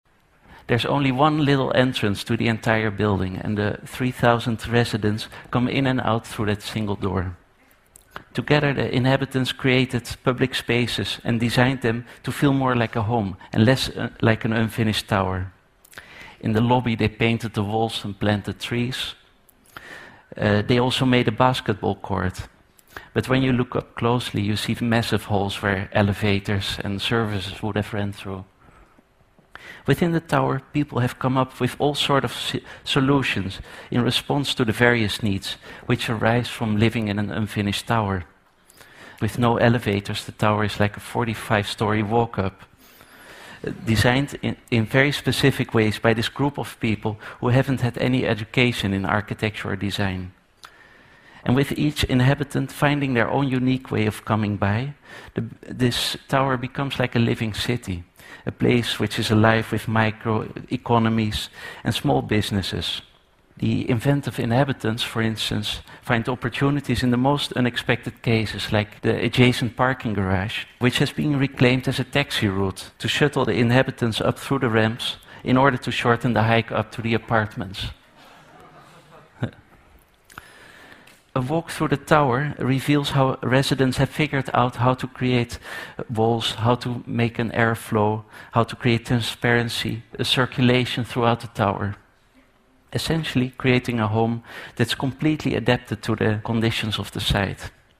TED演讲:特殊环境里的不同寻常的房子(3) 听力文件下载—在线英语听力室